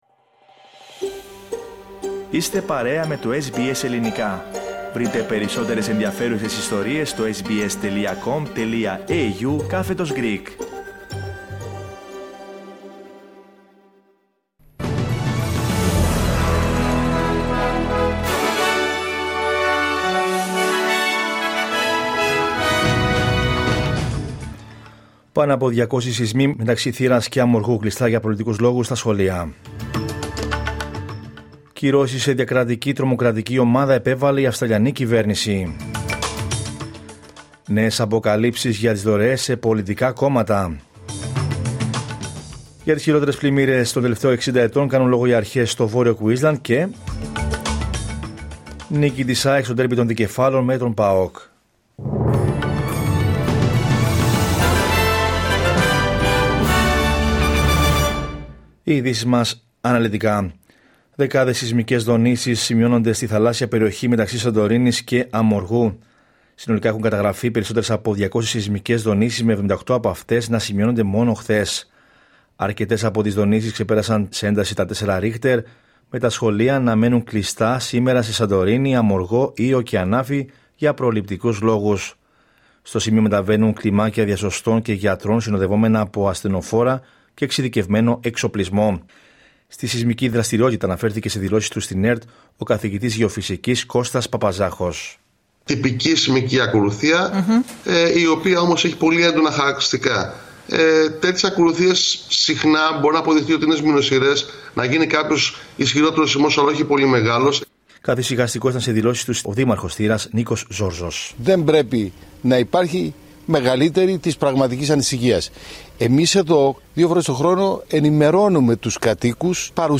Δελτίο Ειδήσεων Δευτέρα 3 Φεβρουαρίου 2025